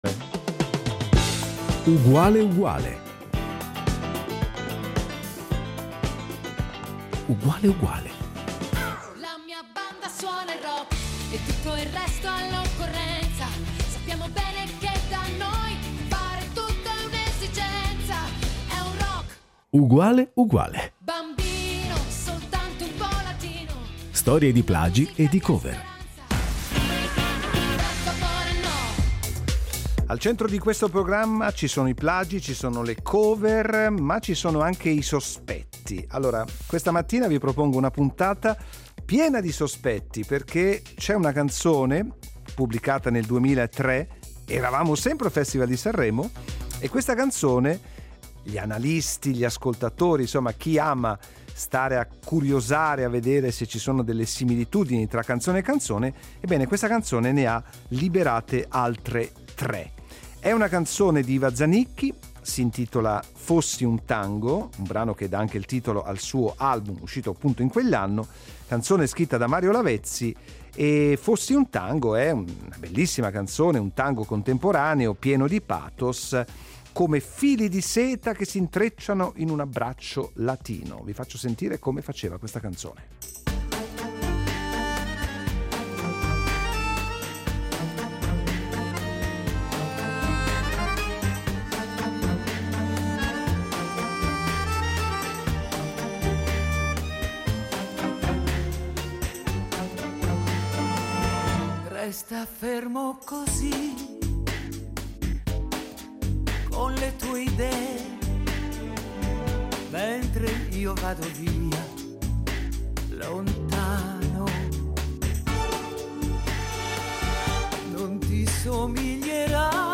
Torniamo al 2003, quando Iva Zanicchi presenta Fossi un tango a Sanremo, brano che dà il titolo al suo album dedicato al mondo intenso e sensuale del tango. Il pezzo, uno dei quattro inediti, è un tango contemporaneo carico di pathos, con melodie tese come fili di seta.
Ascoltandolo, è naturale pensare al magnetismo del Libertango di Piazzolla, presente nello stesso album, con la sua pulsazione sensuale.